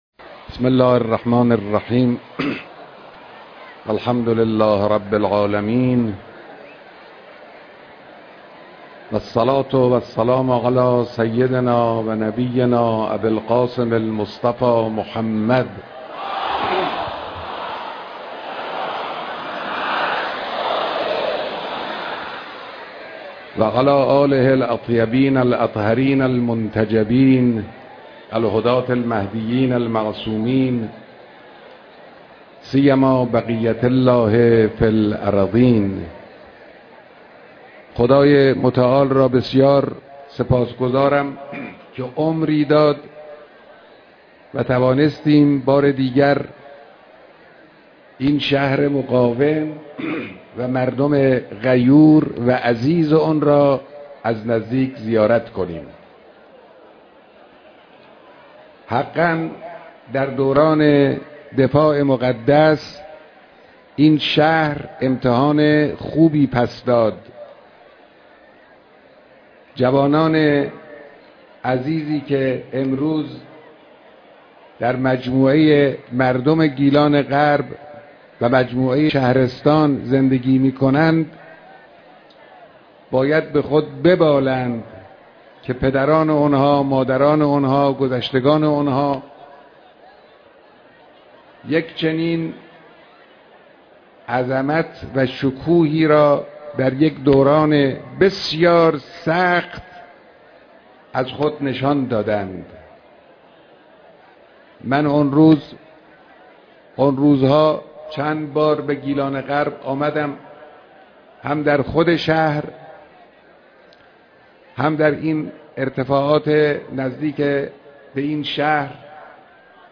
اجتماع عظیم مردم گیلانغرب در مراسم دیدار با رهبر معظم انقلاب
بیانات در اجتماع مردم گیلان غرب